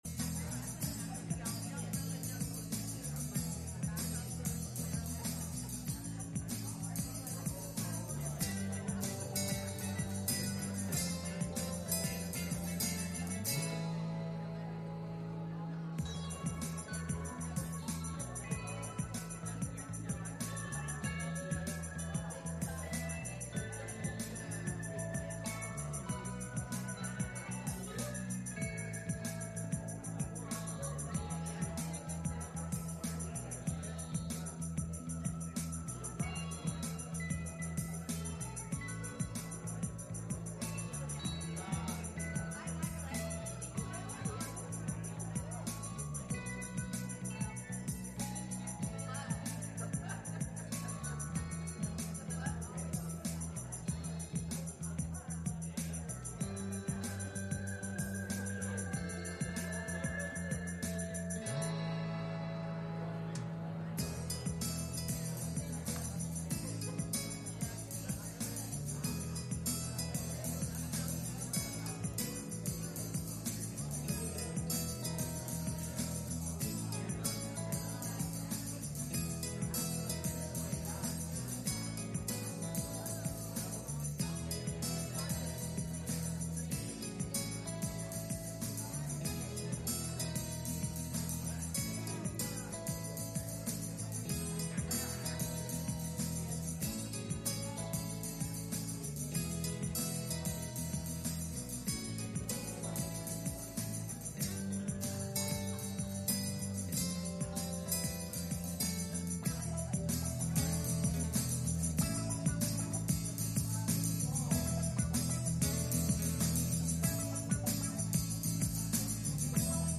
Passage: Ephesians 4:26 Service Type: Sunday Morning